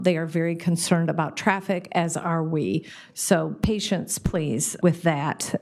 Mayor Patricia Randall says you may want to seek alternative routes.